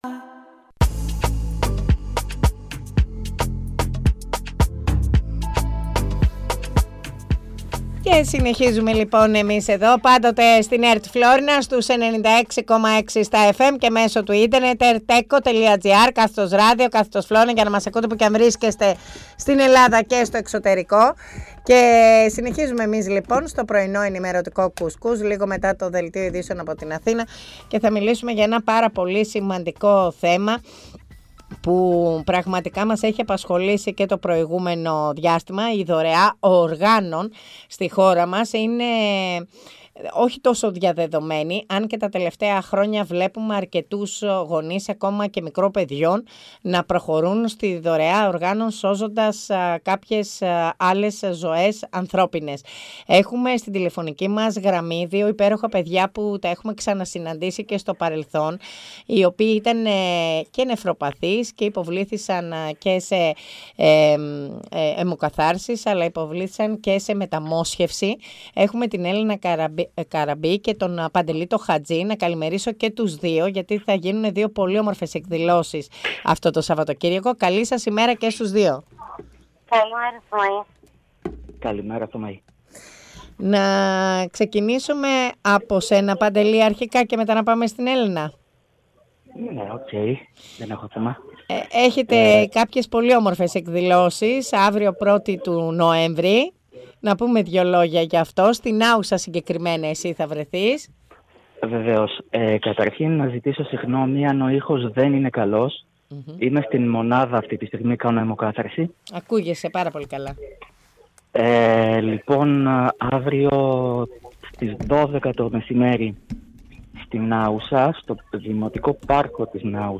Στην σημερινή εκπομπή μιλάμε με νεφροπαθείς και μεταμοσχευμένους για τη σημαντικότητα της δωρεάς οργάνων με αφορμή την Παγκόσμια Ημέρα Δωρεάς Οργάνων, 1η Νοεμβρίου.
Πρωϊνό ενημερωτικό “κους-κους” με διαφορετική ματιά στην ενημέρωση της περιοχής της Φλώρινας και της Δυτικής Μακεδονίας, πάντα με την επικαιρότητα στο πιάτο σας.